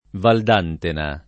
[ vald # ntena ]